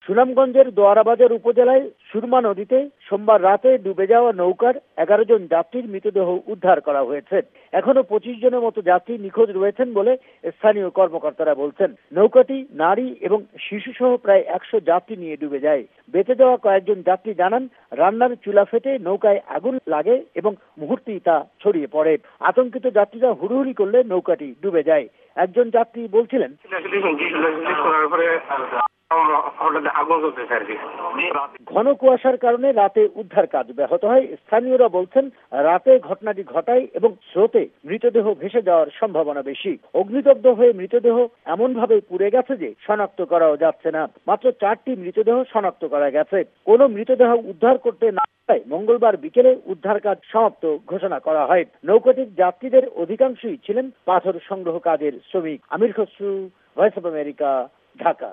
মঙ্গলবার: ঢাকা সংবাদদাতাদের রিপোর্ট